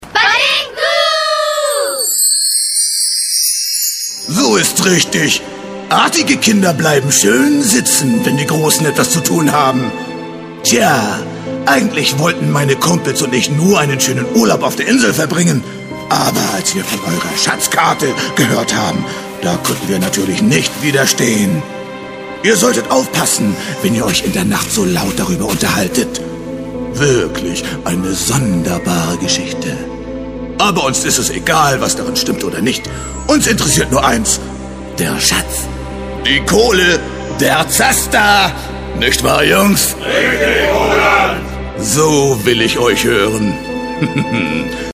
der Sprecher mit warmer, sonoriger und ausdrucksstarker Stimme für Werbung, Voice-off/-over, Hörbücher, Synchron und mehr...
Sprechprobe: Werbung (Muttersprache):
english (us) voice over artist